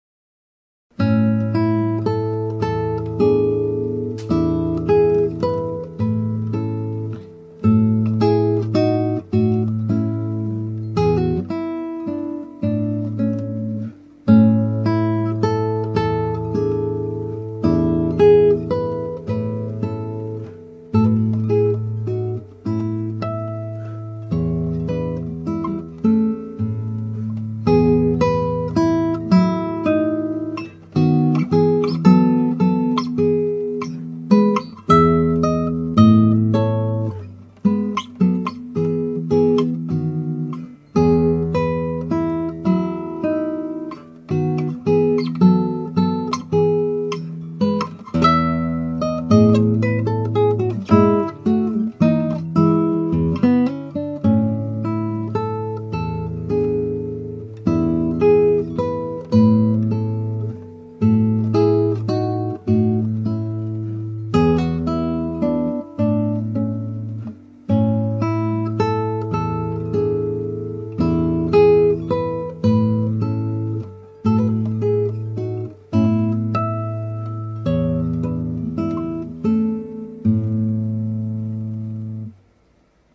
(アマチュアのクラシックギター演奏です [Guitar amatuer play] )
いざ録音して聴いてみるとちっともリズムに乗れていない。
今回アップした録音では、いろいろな方にお叱りを受けると思いますが小節、フレーズの終わりの休符を無くし伸ばすという演奏にしました。
これで消音が出来ていれば私としては現時点でよいのですがここは未熟です。
私の演奏録音は、だいたいオンマイク(近接セッティング)、低音カットフィルタオンで録っています。
この曲の録音ではエフェクトも掛けていません。